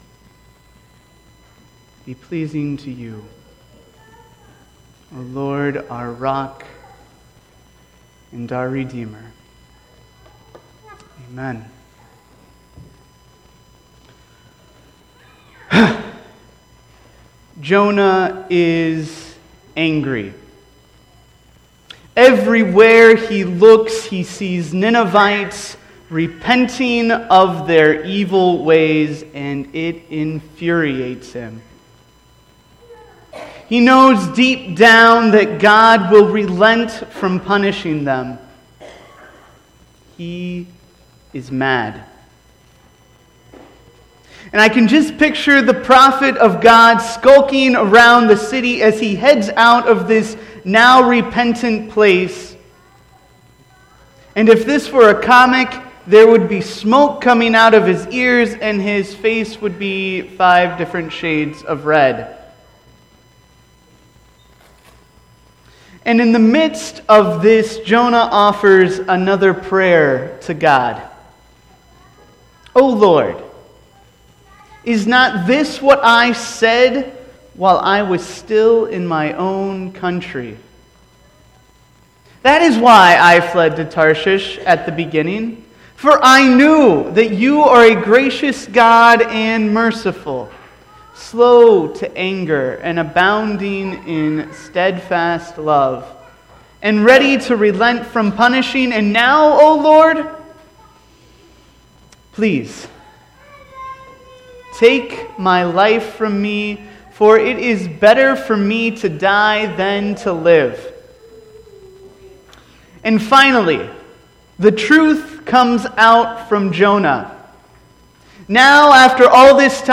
From the campout weekend.